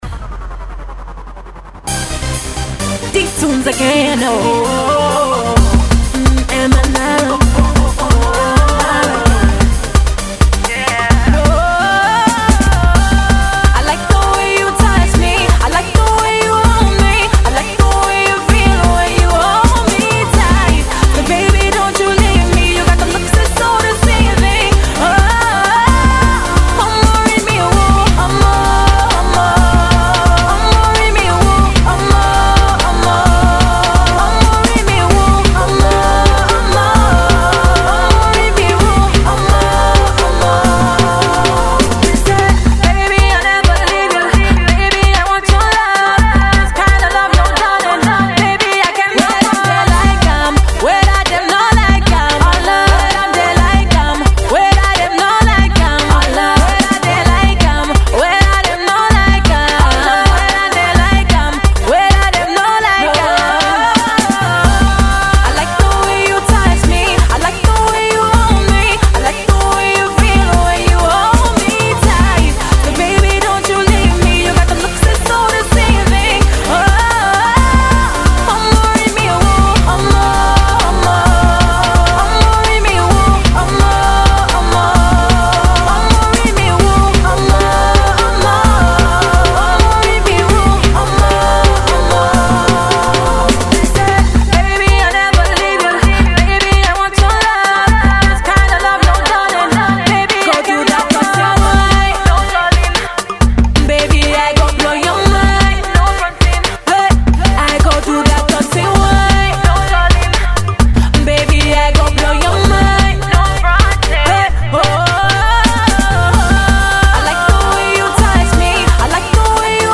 two blazing love ballads laced with hefty beats
an uptempo love song you can dance to